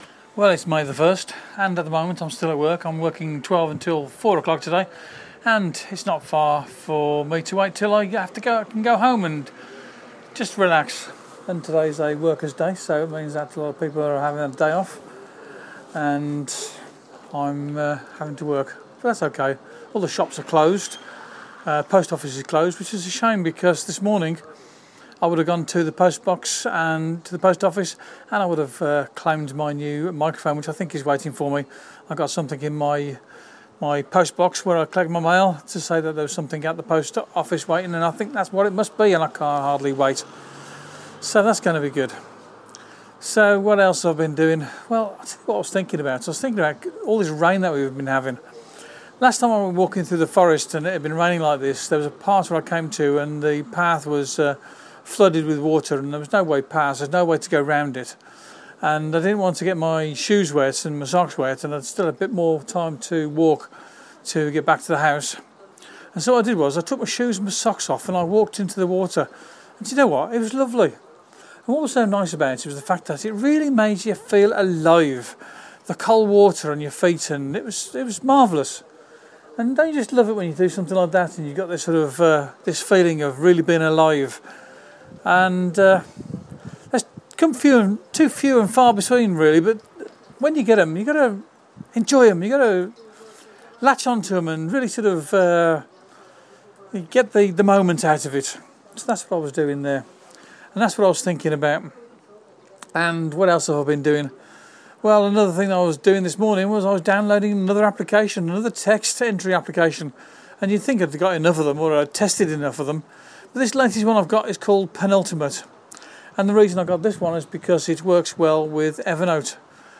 It's a happy sunny day at the campsite by the beach in Platja D'Aro